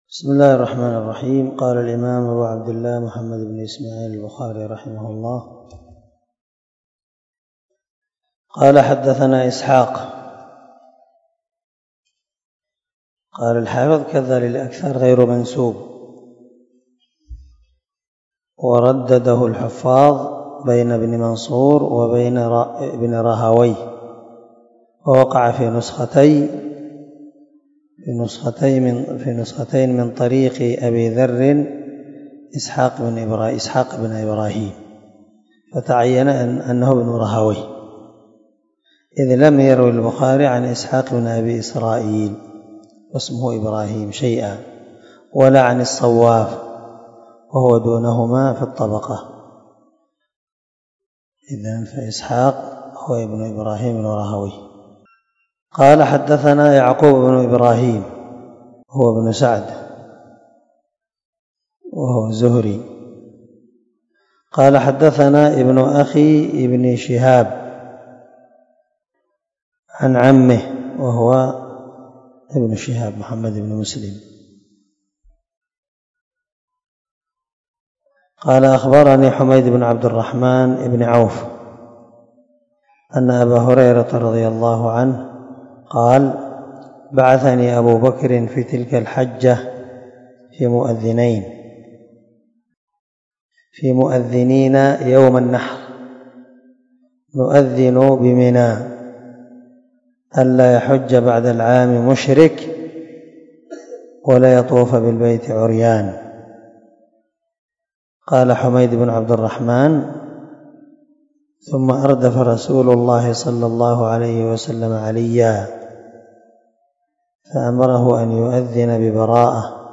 282الدرس 15 من شرح كتاب الصلاة حديث رقم ( 369 ) من صحيح البخاري